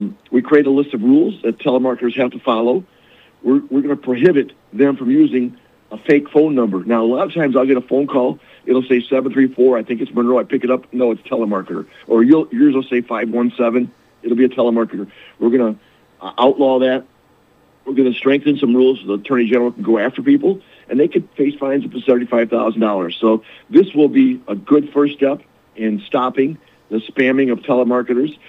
During a recent radio interview, State Sen. Joe Bellino outlined what the legislation is designed to do and why lawmakers say it’s a necessary first step.